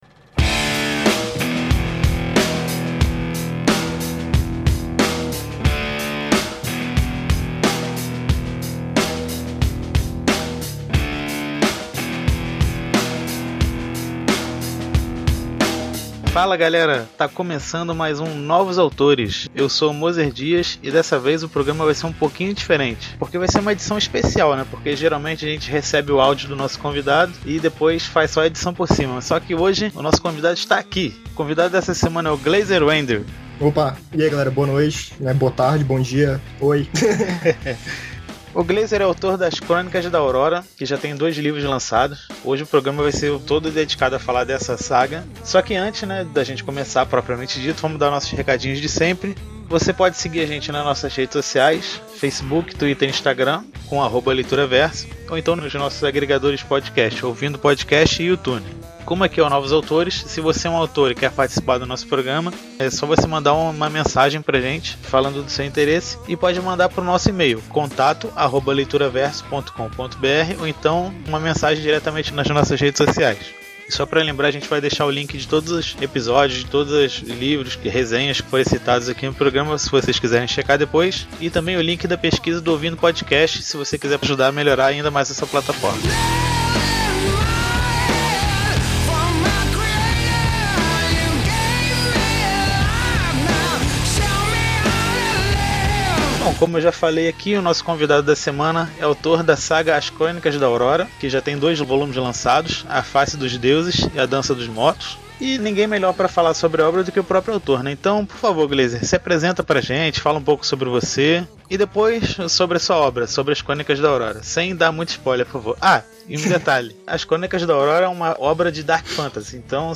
Novos Autores 31: entrevista